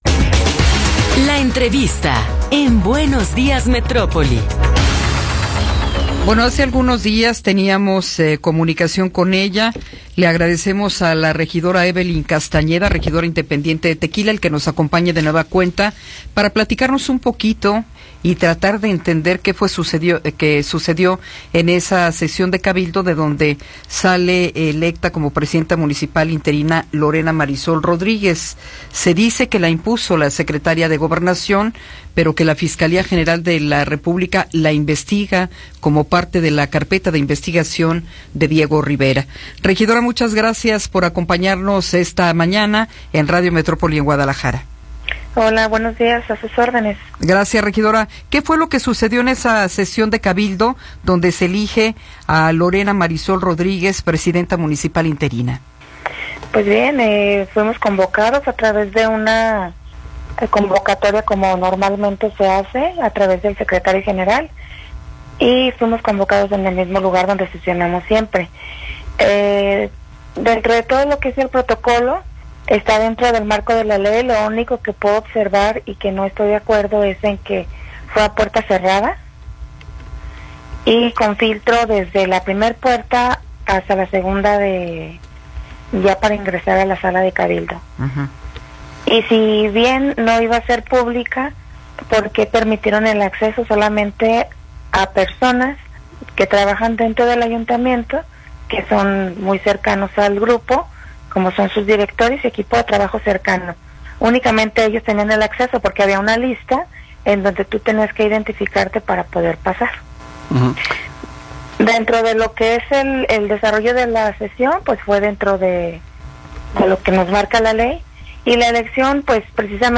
Entrevista con Evelyn Castañeda
Evelyn Castañeda, regidora independiente en Tequila, nos habla sobre el nombramiento de Lorena Marisol Rodríguez Rivera como alcaldesa interina de Tequila.